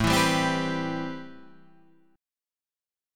Am9 chord